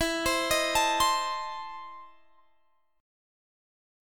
EM7sus4#5 Chord
Listen to EM7sus4#5 strummed